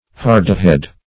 hardihead - definition of hardihead - synonyms, pronunciation, spelling from Free Dictionary
Hardihead \Har"di*head\ (h[aum]r"d[i^]*h[e^]d)